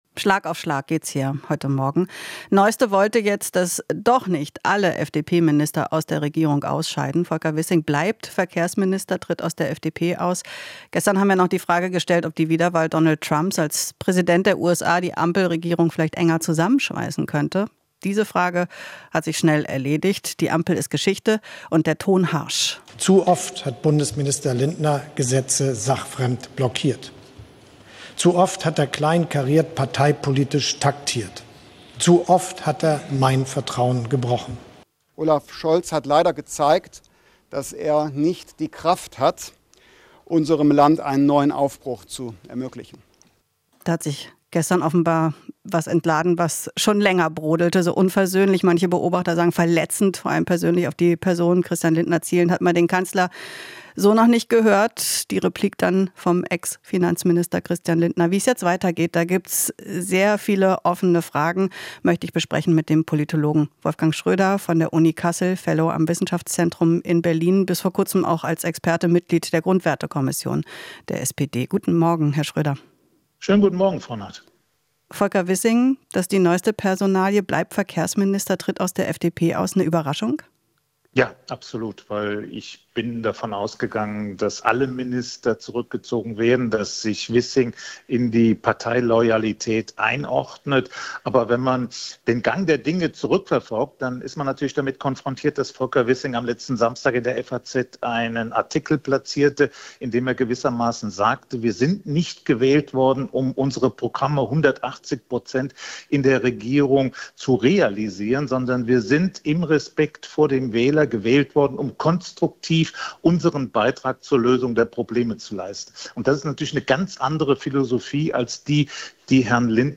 Interview - Politologe: FDP hat Momentum herbeigefleht